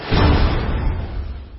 铁匠-关卡刚开始音效.mp3